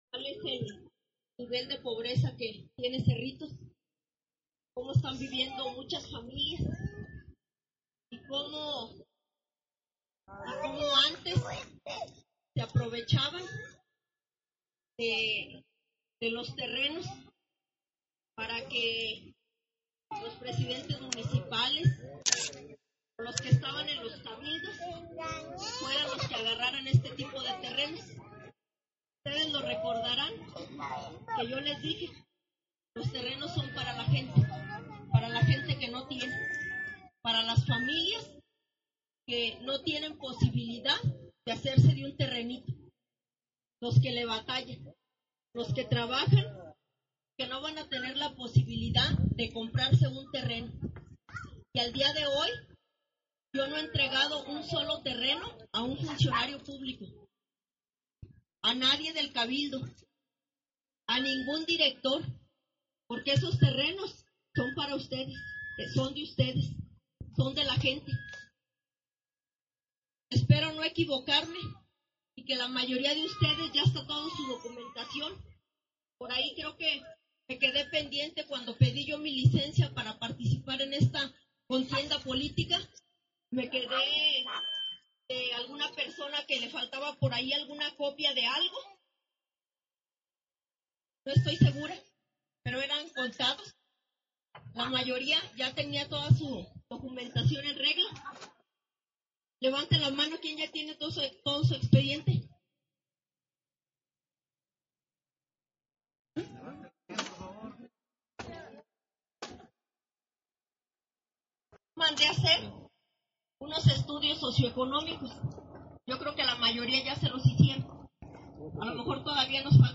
Vecinos que forman la colonia Nueva Satélite, se dieron cita a reunión con la representante de la Coalición “Juntos Haremos Historia”. La cita sucedió el viernes 14 de mayo por la tarde, en ese sector loteado, que cada vez mira como poco a poco se lleva a cabo el desarrollo del entorno.
Mitin-Colonia-Nueva-Satelite.mp3